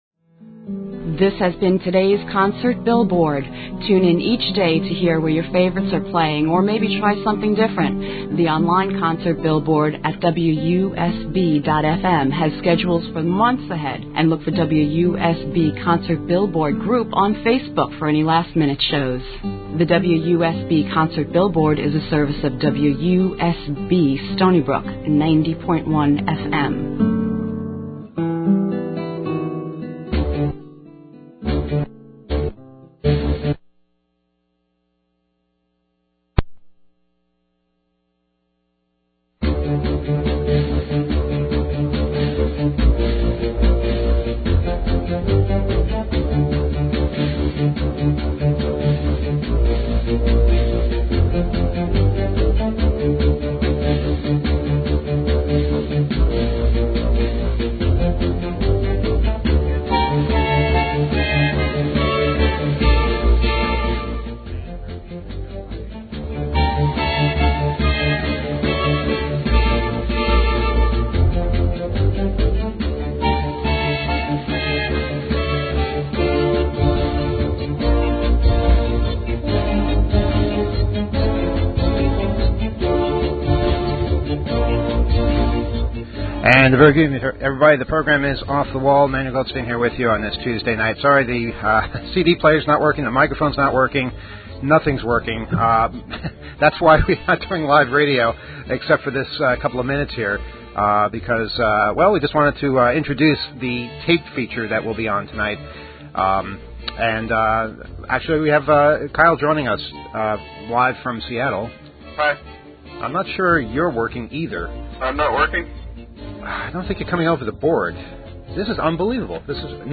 All kinds of technical problems at the start of the show
tonight's show was recorded next to busy train tracks in Zurich
a freight train finally passes, the sound of bells